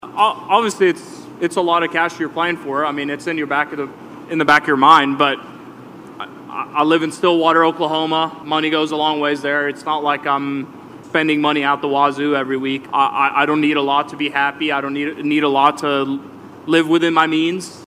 Hovland, who still makes his home in Stillwater, talks about the big payday.